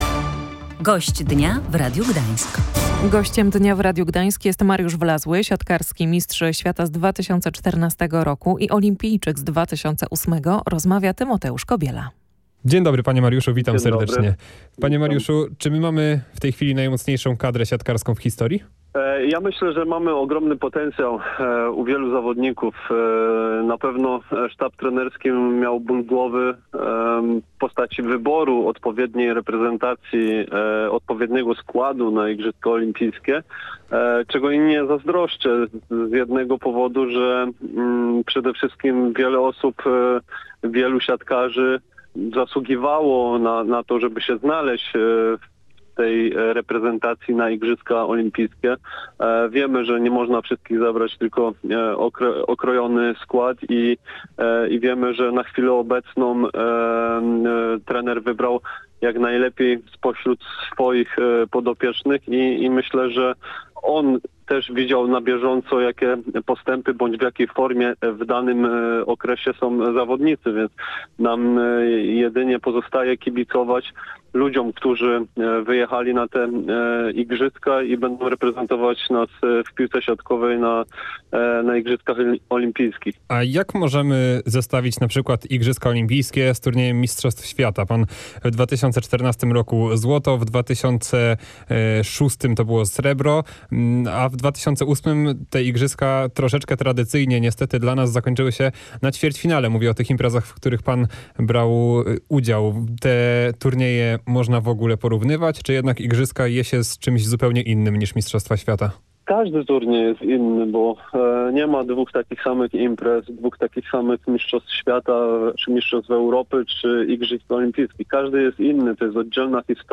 mówił Gość Dnia Radia Gdańsk Mariusz Wlazły, siatkarski mistrz świata z 2006 roku i olimpijczyk z Pekinu